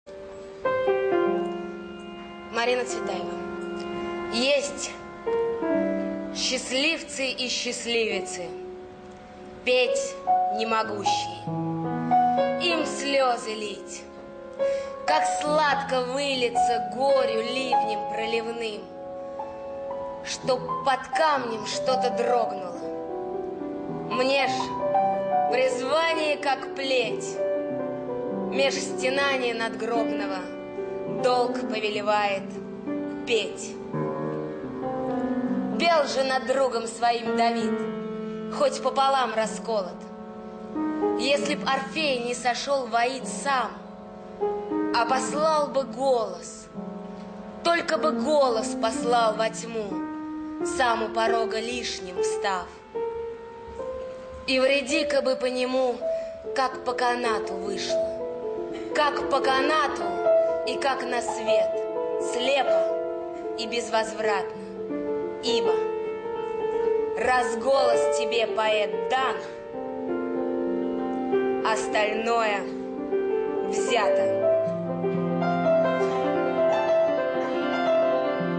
1. «Читает Чулпан Хаматова – Есть счастливцы и счастливицы Марина Цветаева РадиоСтихи» /